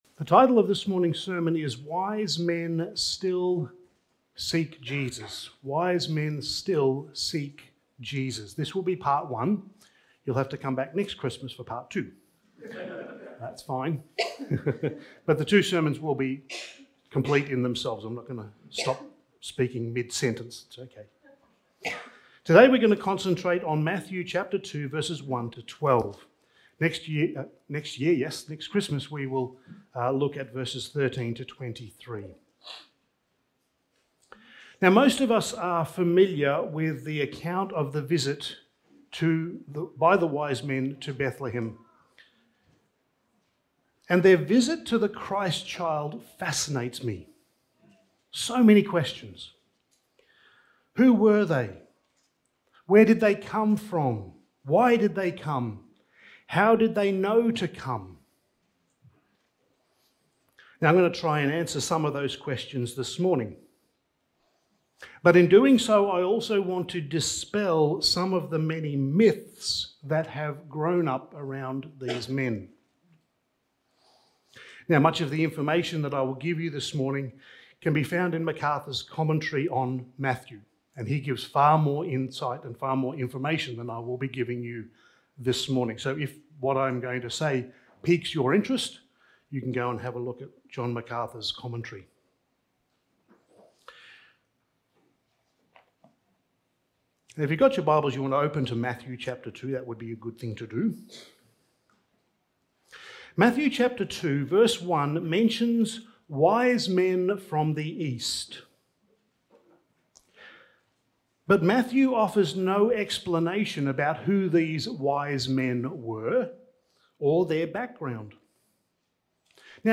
Passage: Matthew 2:1-12 Service Type: Sunday Morning